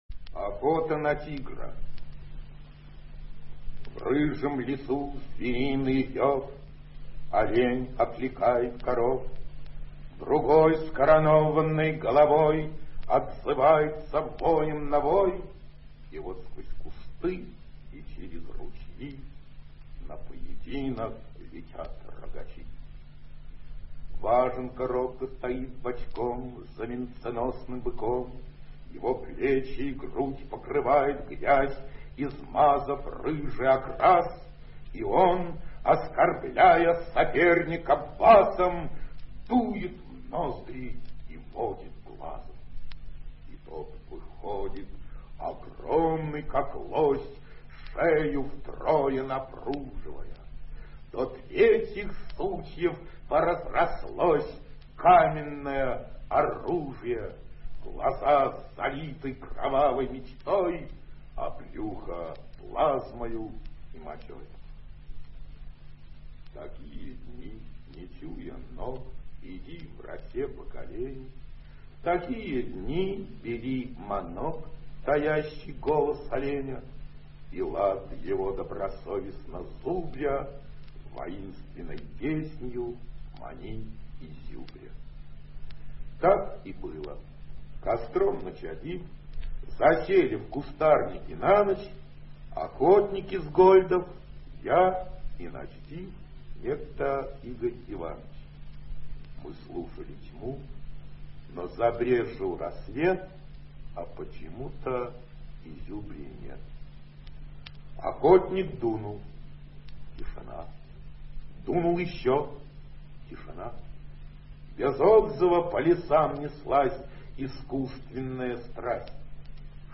Илья Сельвинский – Охота на тигра (читает автор)